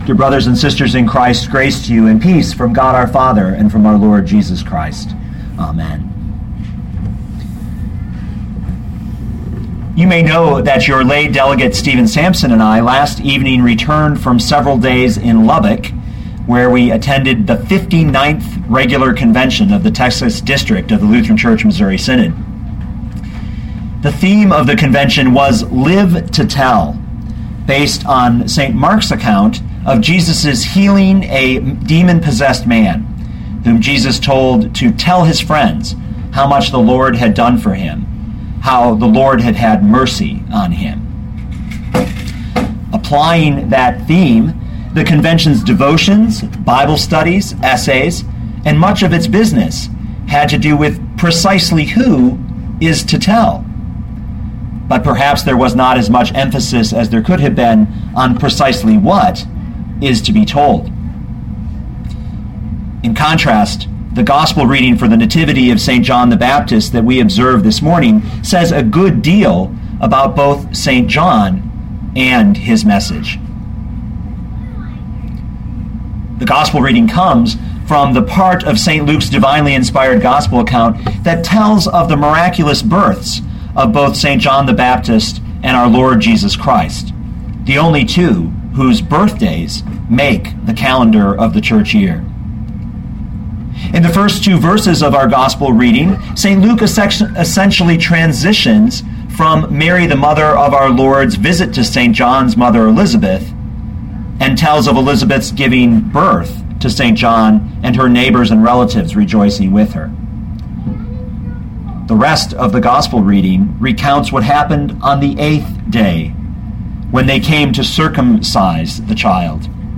2012 Luke 1:57-80 Listen to the sermon with the player below, or, download the audio.